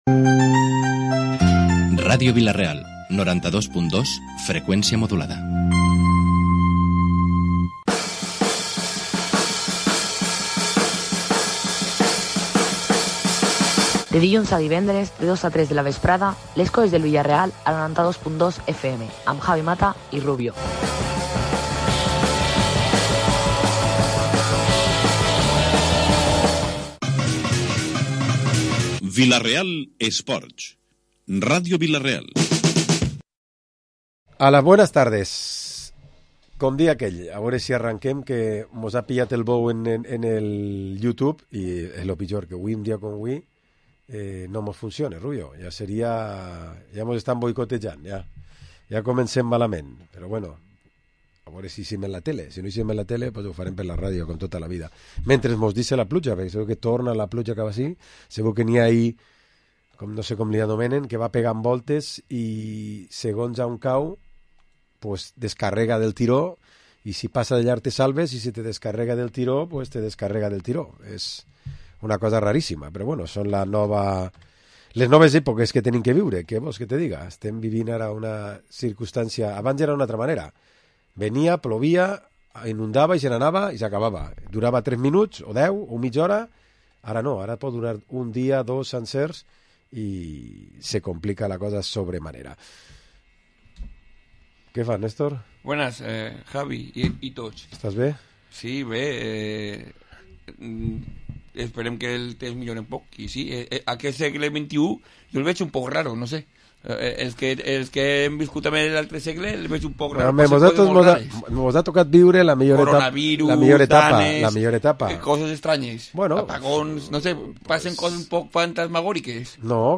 Programa Esports dilluns tertúlia 29 de setembre de 2025